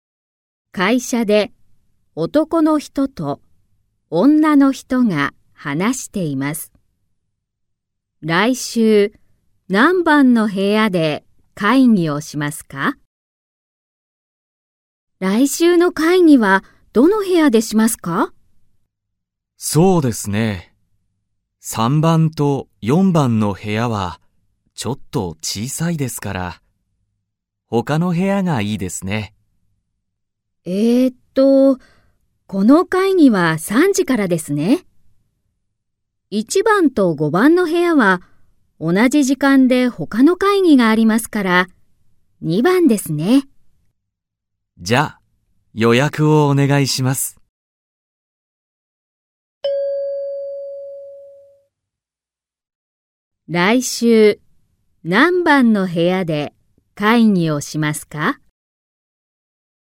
I.聴解 (Nghe hiểu)